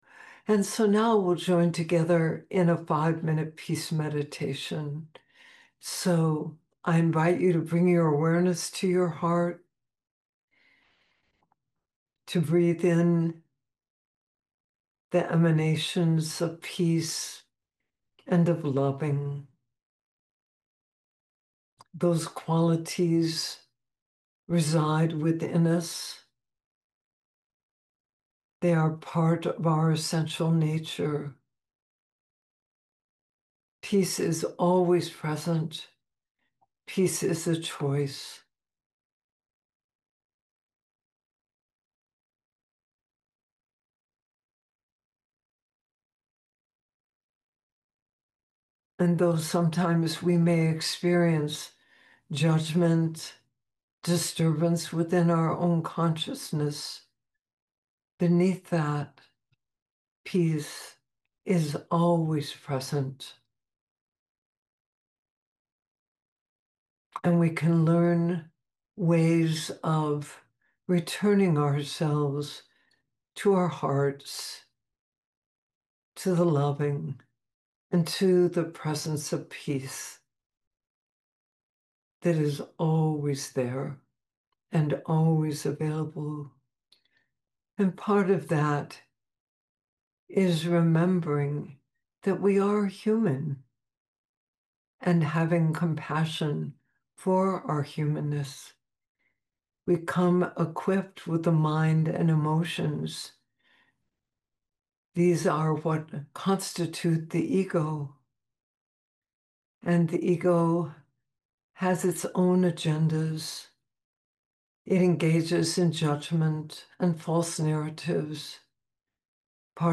5-MINUTE PEACE MEDITATION
Light Invocation & Centering Practice